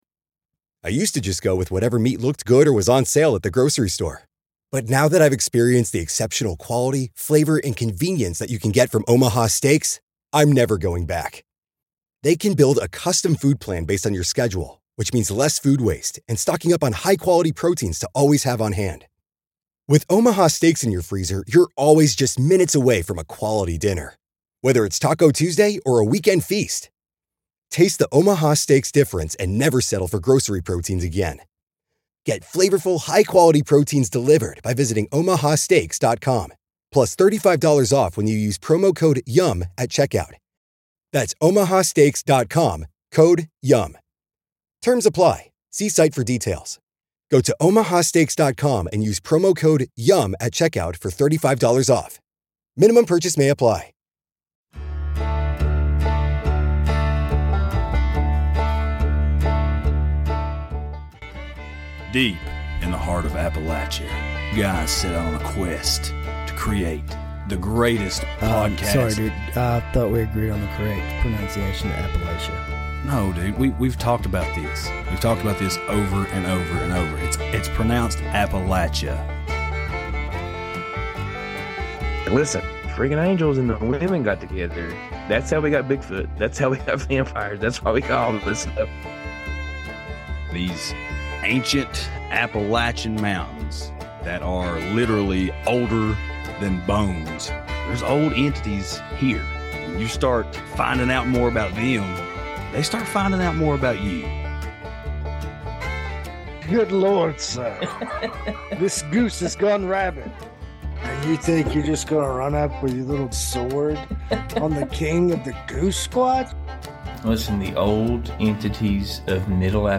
The guys chat it up about CERN located right outside Geneva, Switzerland. CERN is a an organization of scientists that delve into particle and Quantum physics in an attempt to explore and define the universe and how it was made. There's a whole lot of weird that surrounds CERN though.